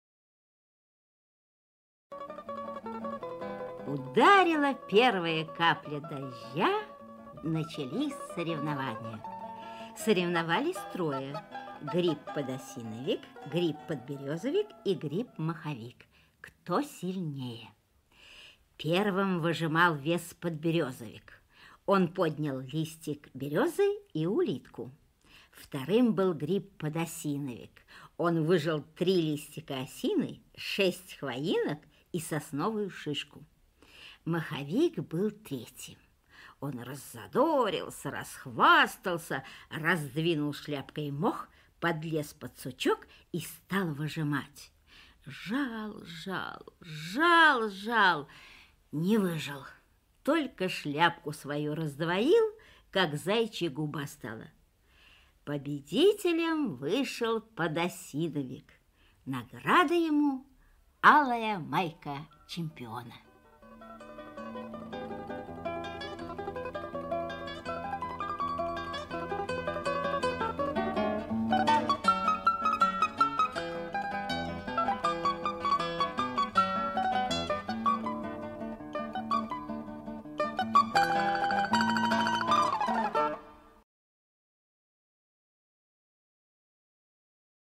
Аудиосказка «Лесные силачи»